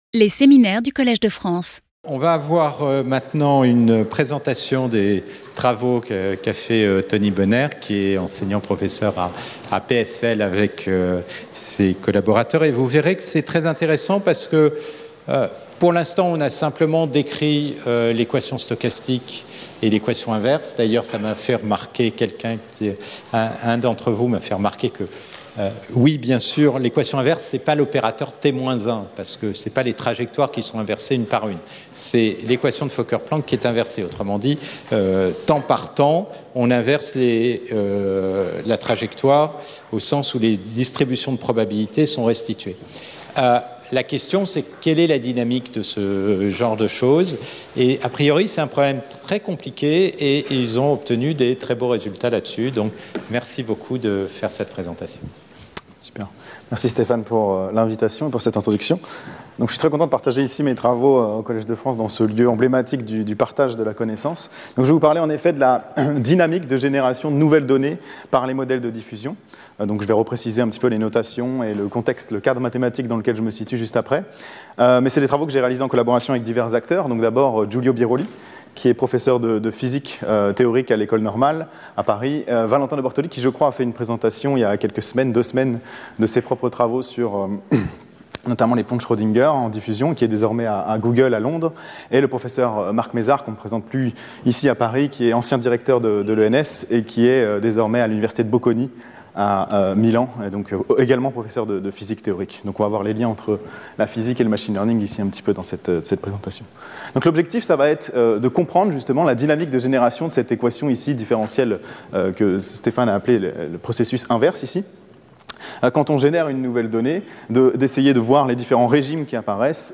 In this talk, we will briefly introduce diffusion models before analyzing the generation dynamics in a well-controlled high-dimensional case: the mixing of two Gaussians.